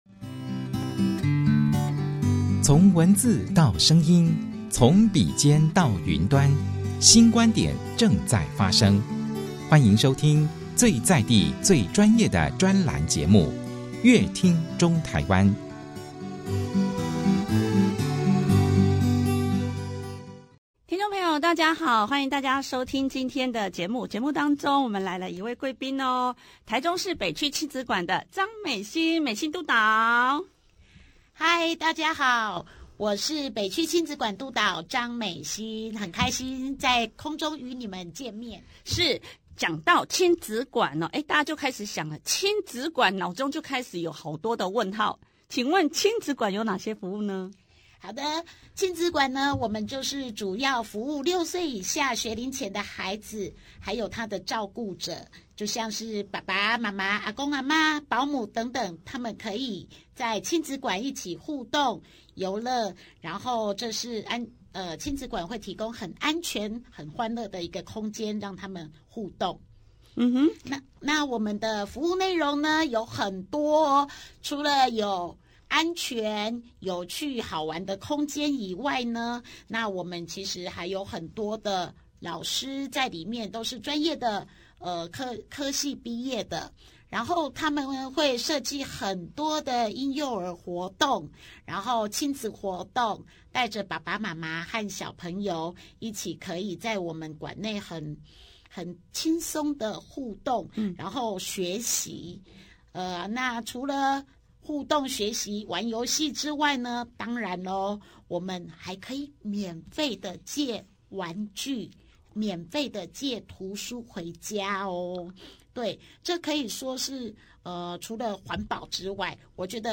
想知道精彩的專訪內容，請鎖定本集節目。